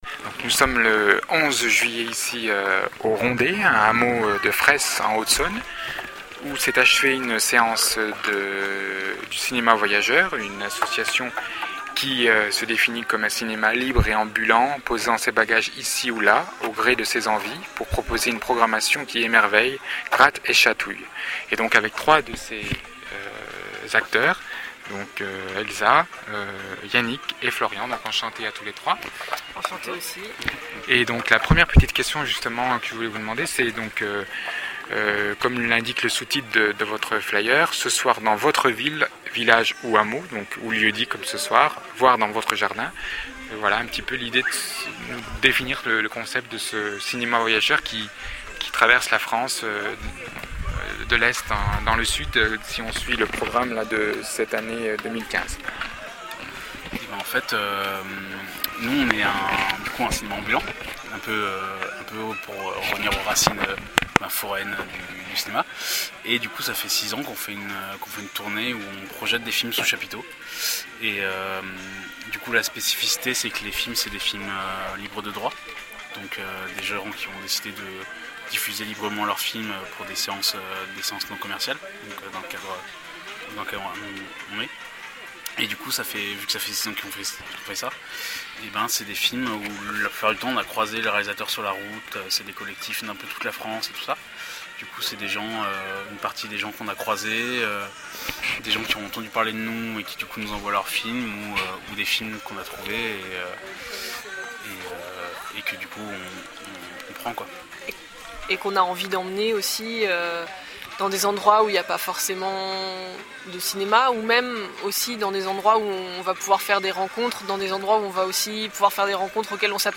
Lieu-dit : Les Rondey, Hameau de Fresse (70)